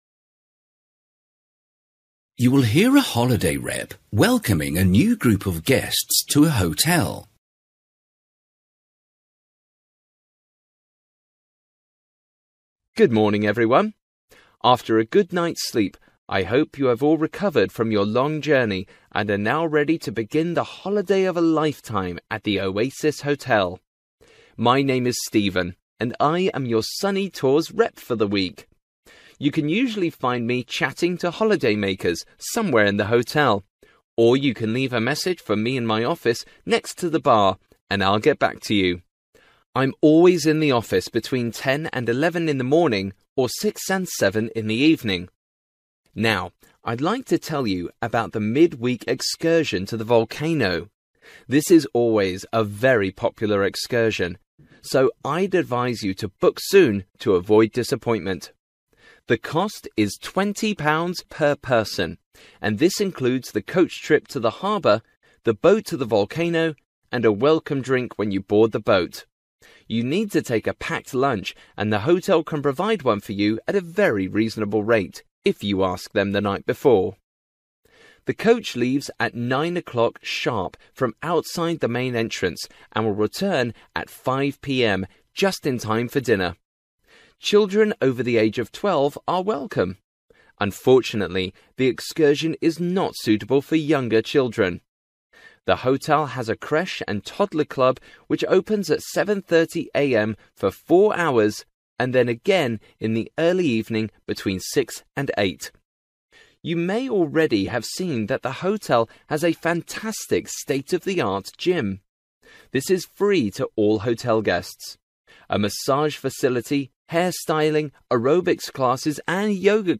You will hear a holiday rep welcoming a new group of guests to a hotel.